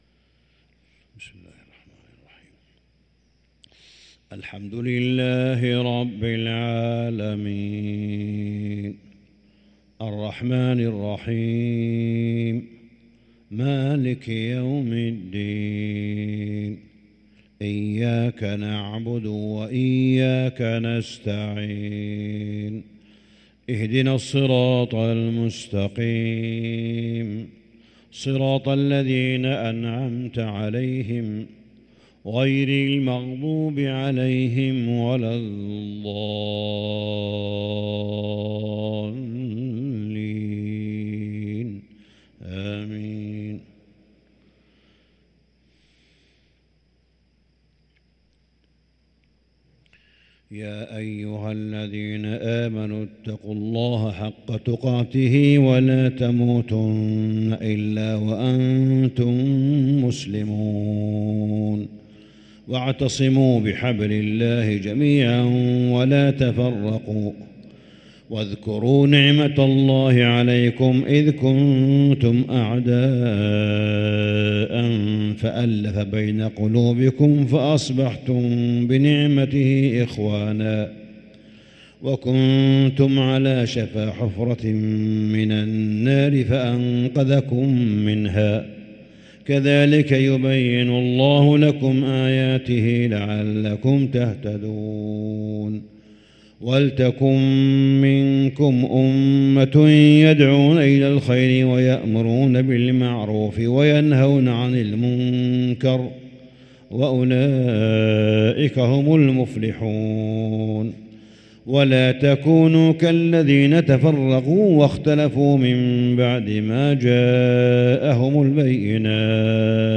صلاة الفجر للقارئ صالح بن حميد 20 رمضان 1444 هـ